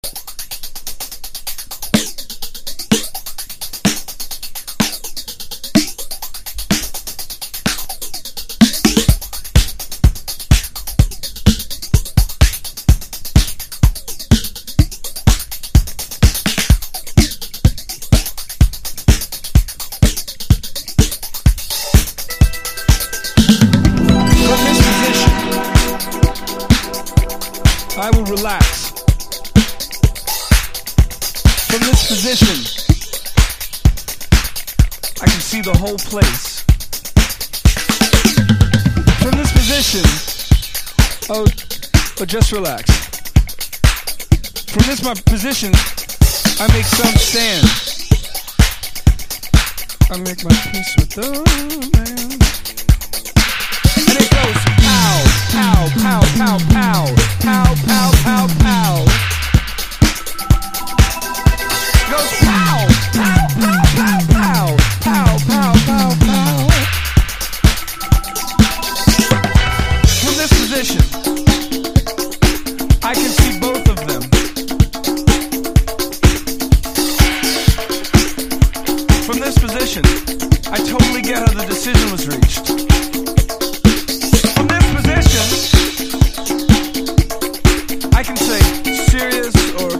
浮遊感あるシンセとベースラインが最高なグルーヴィー・ディスコ・ロック！
# ELECTRO